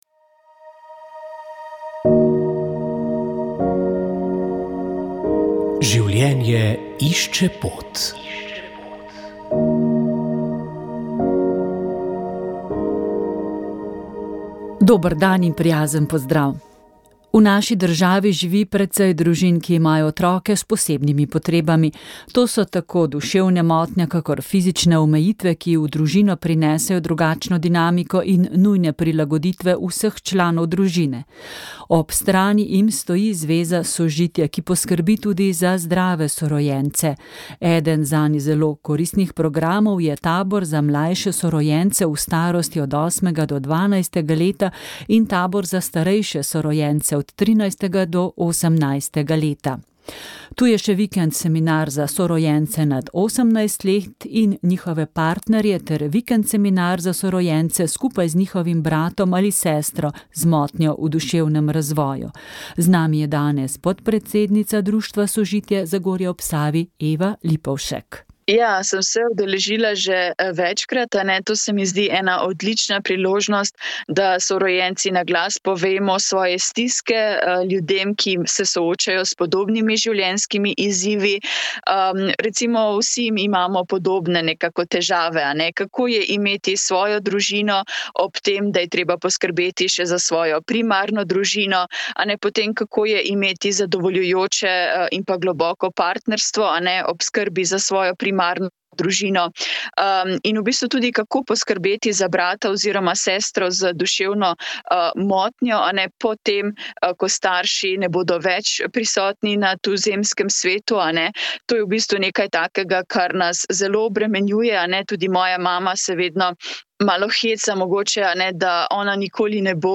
Še tri smo prebrali v tokratni oddaji.